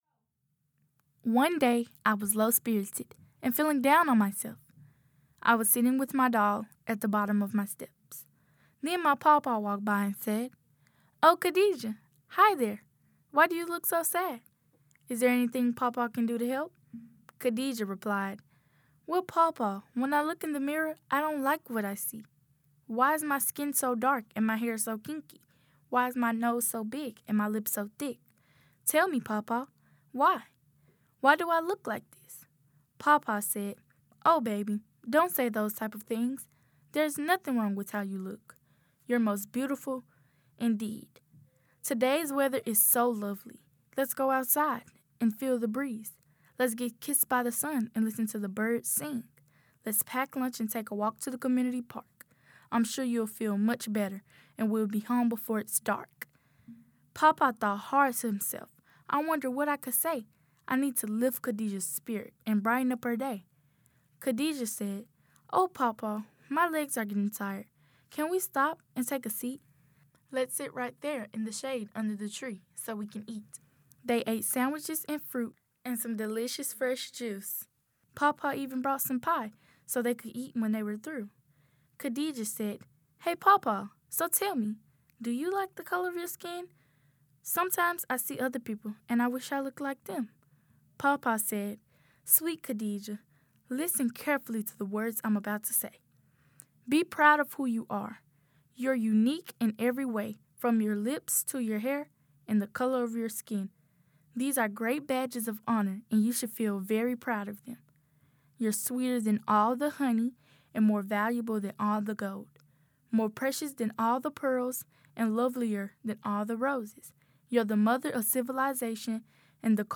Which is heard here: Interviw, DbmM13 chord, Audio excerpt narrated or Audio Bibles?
Audio excerpt narrated